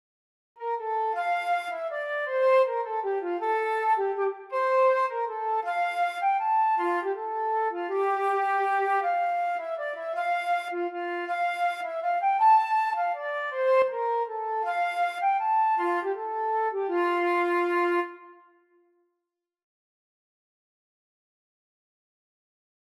Traditional Trad. Take Back the Virgin Page (Irish Air) Flute version
3/4 (View more 3/4 Music)
F major (Sounding Pitch) (View more F major Music for Flute )
Flute  (View more Intermediate Flute Music)
Traditional (View more Traditional Flute Music)